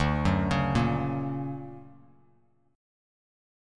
D+M7 chord